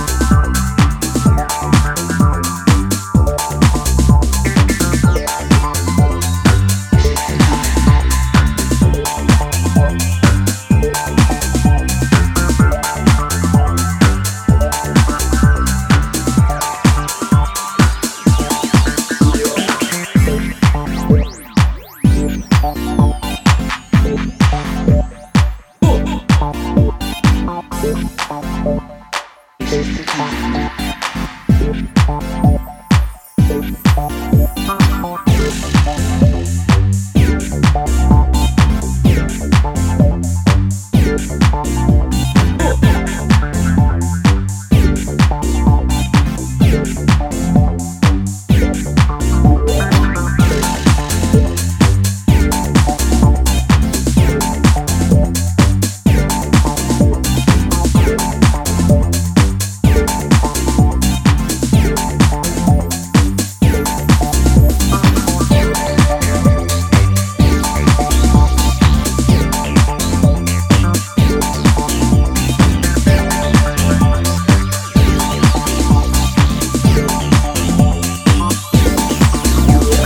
They serve up a selection of retro vibes